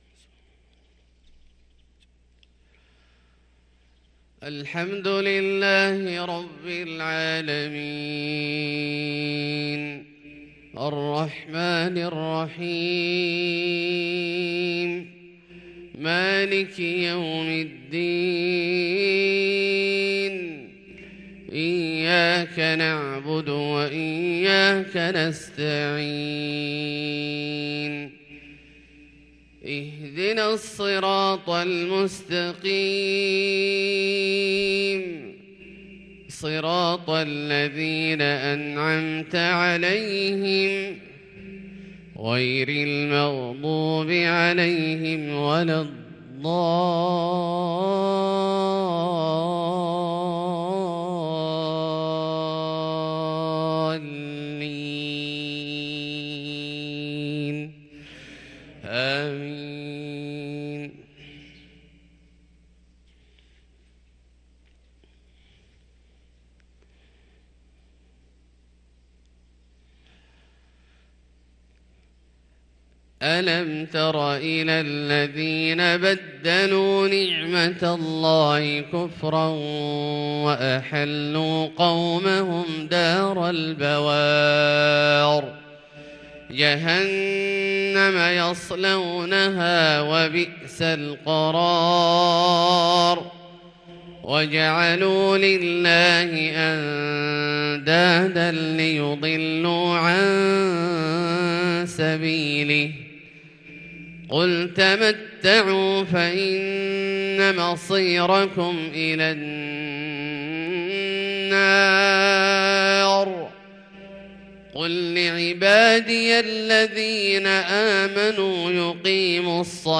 صلاة الفجر للقارئ عبدالله الجهني 14 جمادي الآخر 1443 هـ